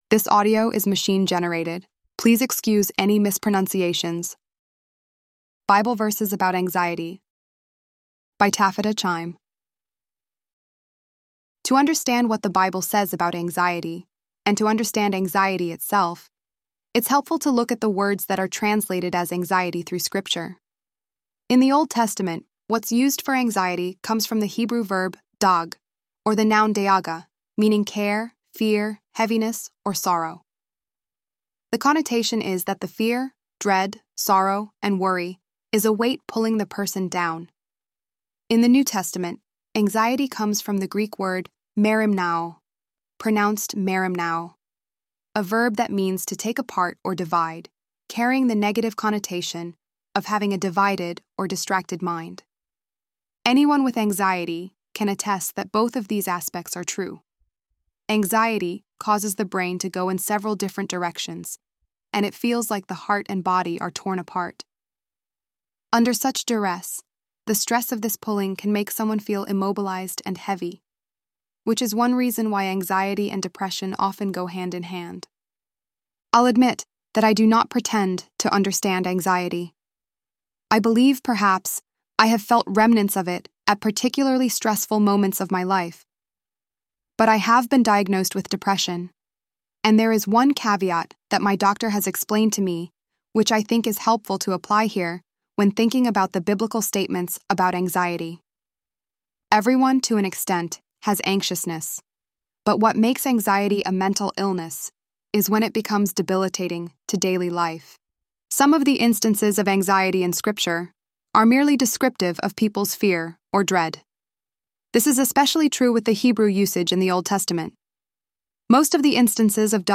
ElevenLabs_12_10.mp3